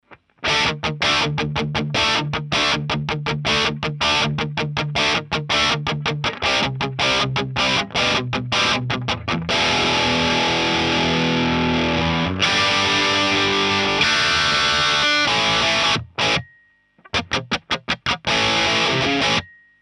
Лампы в преде 12ат и 6н8с: